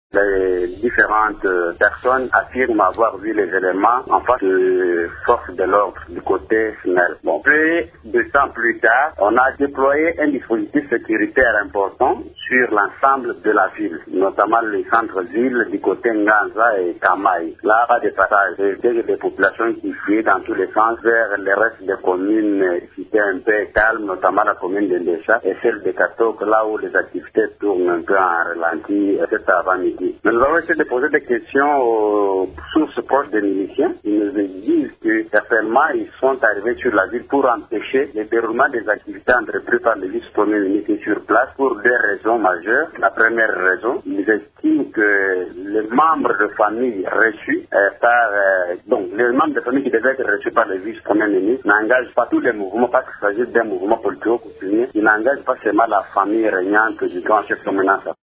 joint au téléphone